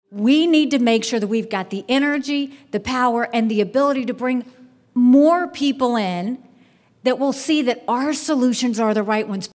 Urbandale, Iowa — Former South Carolina Governor Nikki Haley has kicked off the Iowa leg of her week-old presidential campaign, suggesting the GOP must be forward-looking and solutions-oriented to win back the White House in 2024.